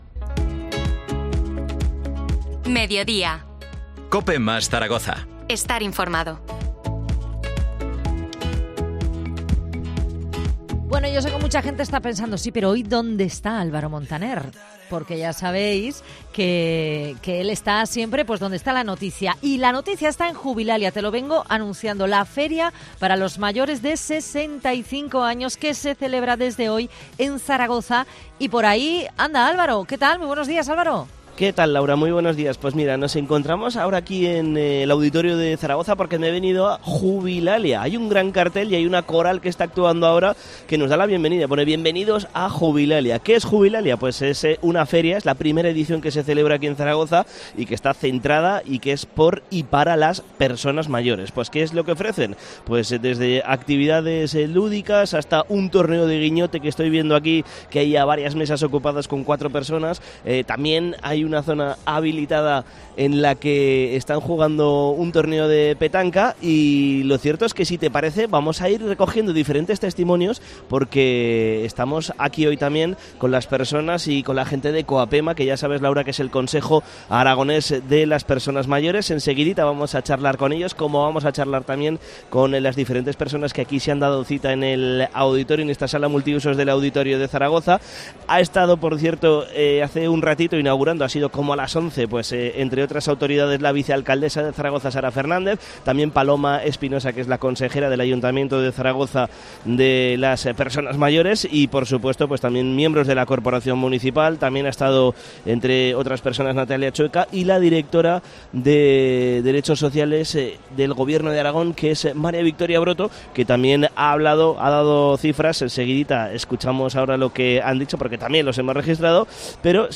COPE Zaragoza visita la feria 'Jubilalia 2023'.